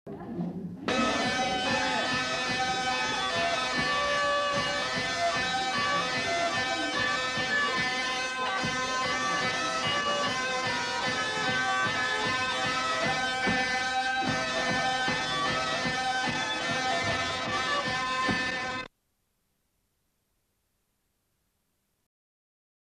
Aire culturelle : Gabardan
Lieu : Gabarret
Genre : morceau instrumental
Instrument de musique : vielle à roue
Danse : java